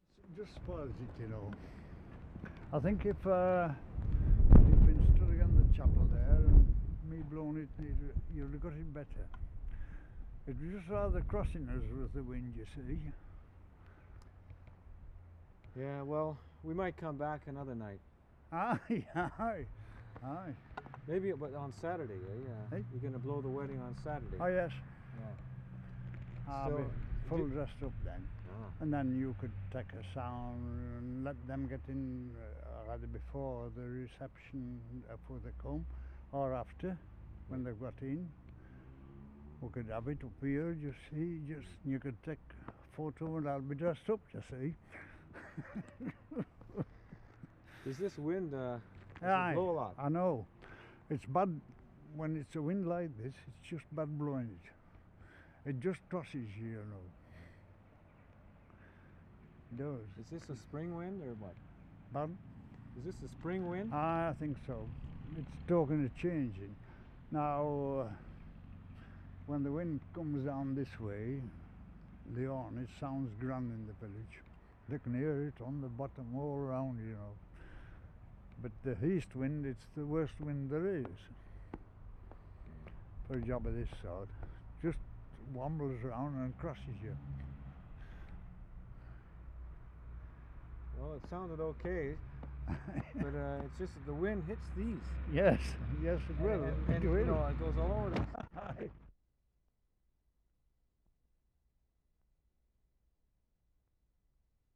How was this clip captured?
Wensleydale, Yorkshire May 7/75